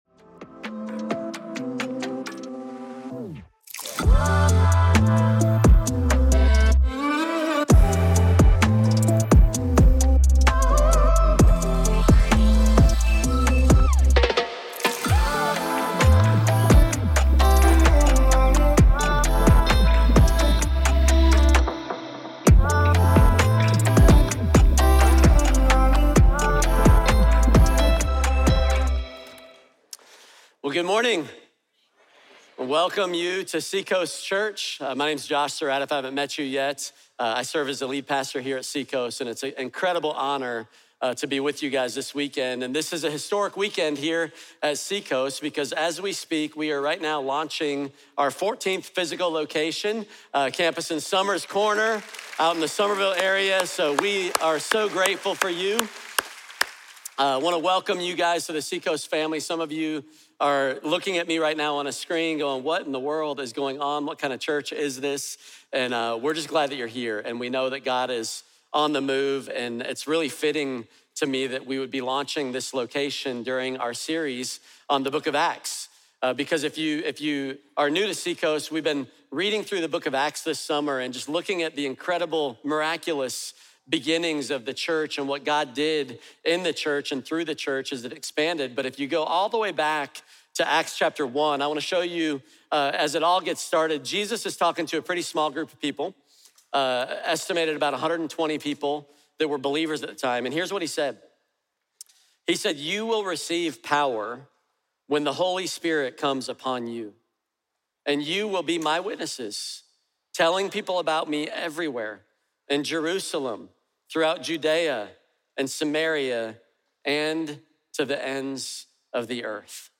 Seacoast Church Weekly Service Audio Podcast